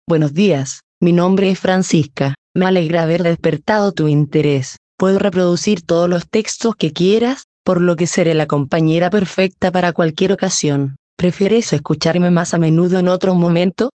Professionelle Sprachausgabe zum Vorlesen und Vertonen beliebiger Texte
Die Stimmen klingen so natürlich, dass sie von menschlichen Sprechern kaum noch zu unterscheiden sind.
• Die Text-to-Speech Software bietet Ihnen alles, was Sie für die professionelle Vertonung benötigen